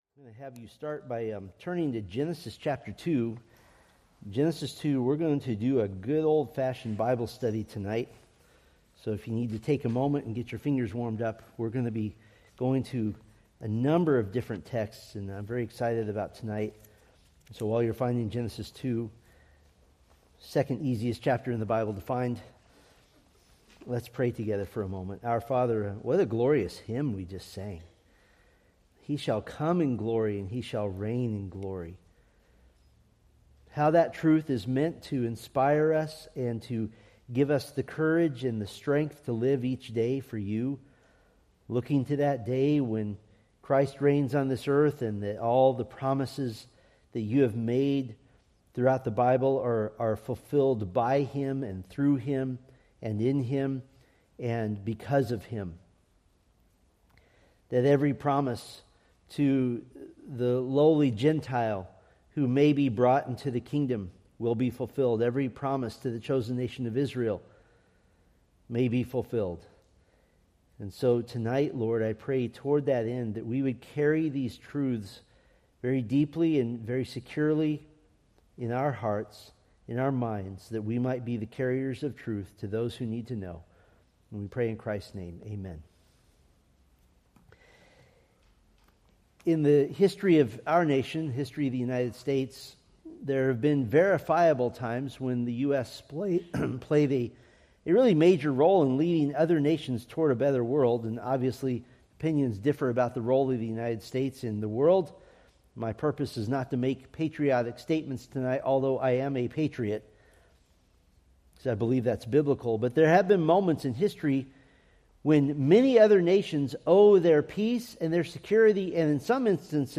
Preached April 12, 2026 from Selected Scriptures